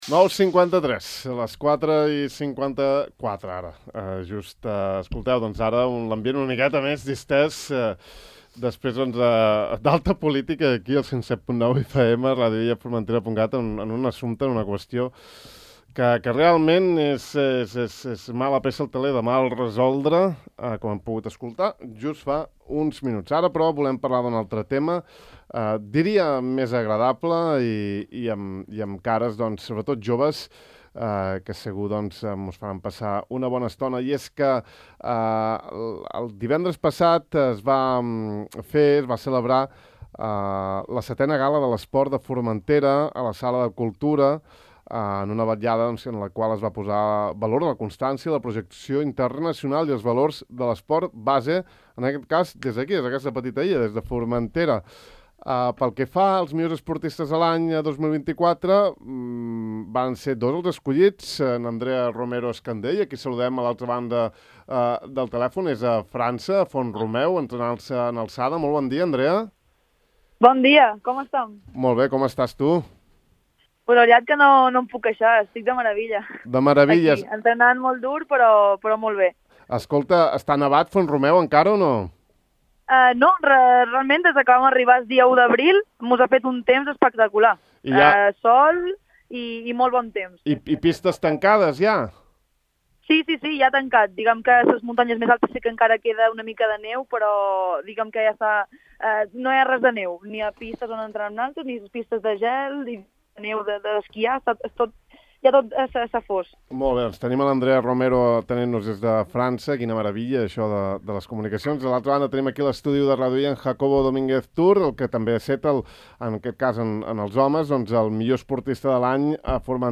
Gala de l'Esport: entrevistam als premiats com a millors esportistes absoluts i sub 18 de 2024